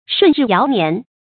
舜日堯年 注音： ㄕㄨㄣˋ ㄖㄧˋ ㄧㄠˊ ㄋㄧㄢˊ 讀音讀法： 意思解釋： 比喻升平盛世。